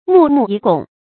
墓木已拱 注音： ㄇㄨˋ ㄇㄨˋ ㄧˇ ㄍㄨㄙˇ 讀音讀法： 意思解釋： 拱：兩手合圍。